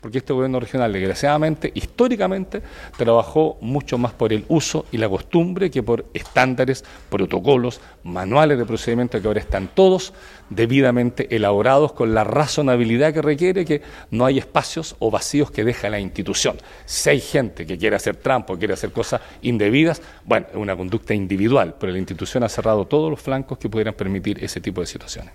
Con sobrios aplausos de su equipo, dio por concluida el Gobernador de Los Lagos, Patricio Vallespín, su última actividad de prensa, con la entrega de detalles del denominado Informe de Traspaso de Mando que se concretará el próximo lunes, para dar paso a la administración de Alejandro Santana en el Gobierno Regional.